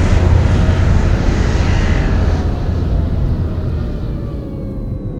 landing.ogg